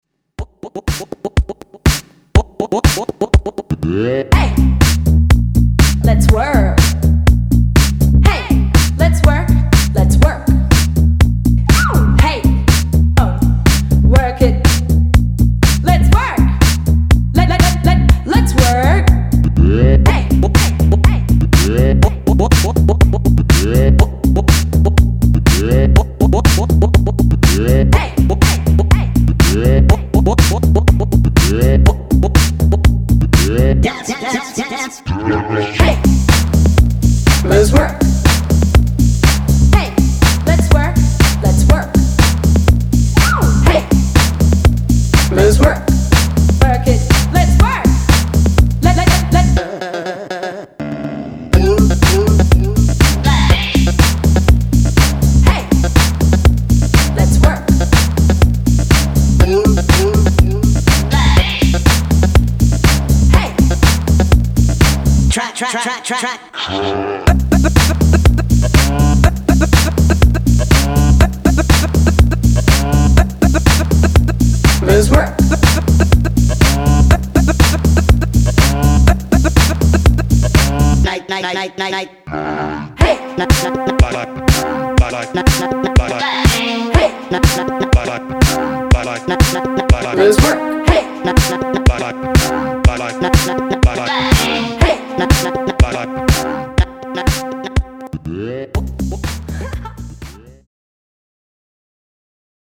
Jacking Chicago track